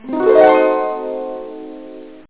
PSION CD 2 home *** CD-ROM | disk | FTP | other *** search / PSION CD 2 / PsionCDVol2.iso / Wavs / HARP ( .mp3 ) < prev next > Psion Voice | 1998-08-27 | 19KB | 1 channel | 8,000 sample rate | 2 seconds
HARP.mp3